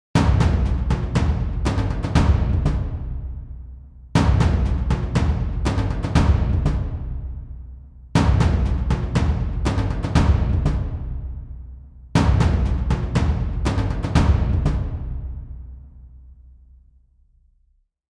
Dramatic-drum-beat-sound-effect.mp3